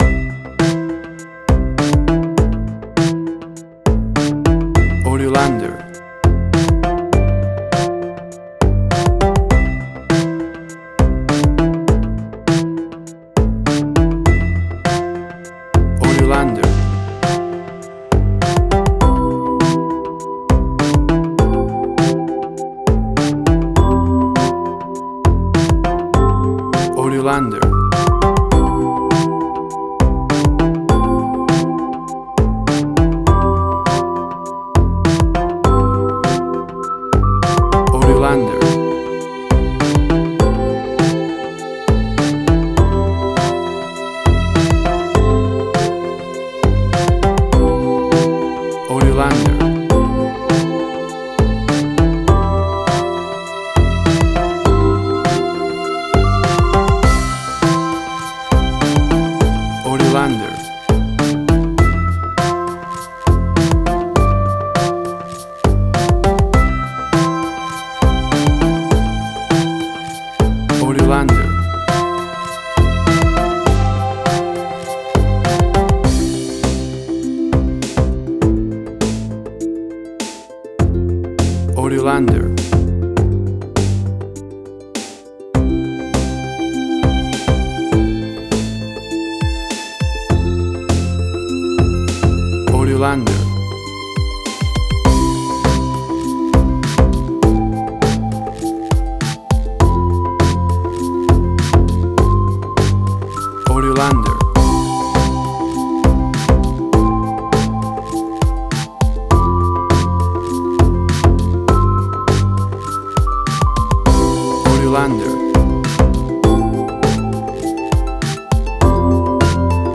with a positive and motivating mood
Tempo (BPM): 101